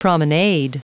Prononciation du mot promenade en anglais (fichier audio)
Prononciation du mot : promenade
promenade.wav